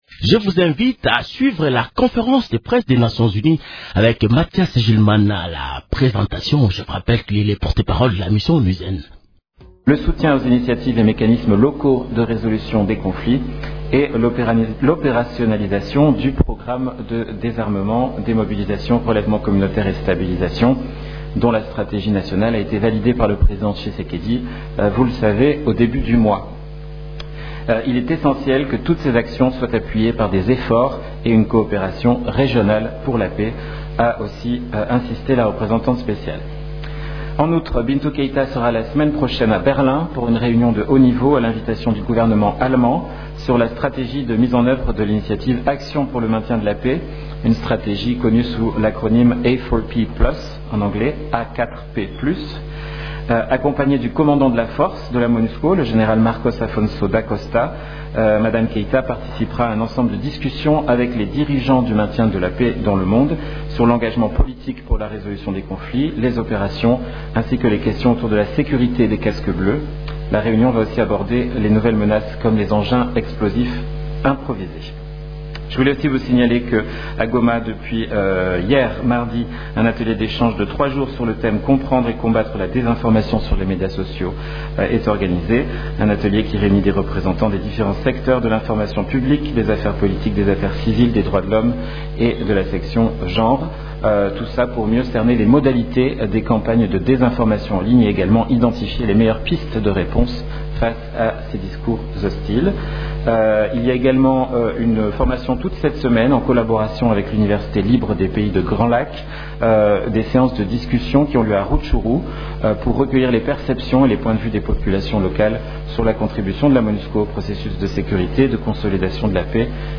Conférence de presse de l’ONU à Kinshasa du mercredi 20 avril 2022